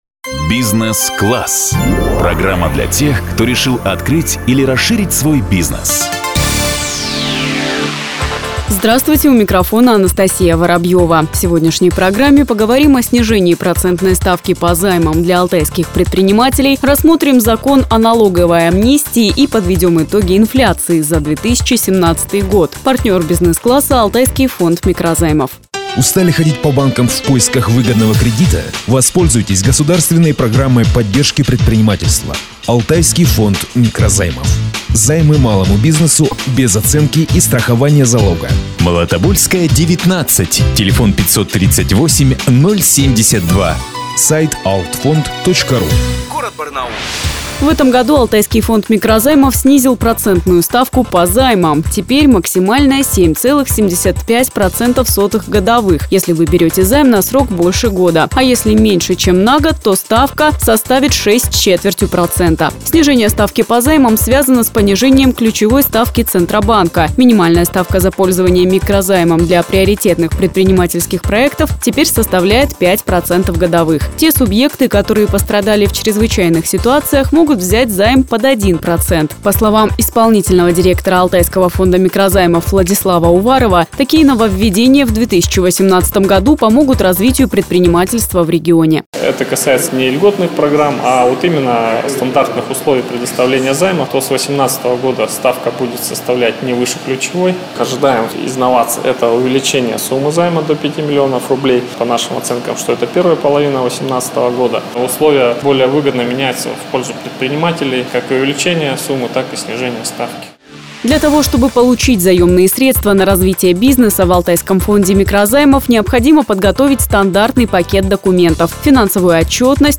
Она выходила в эфир 15 января.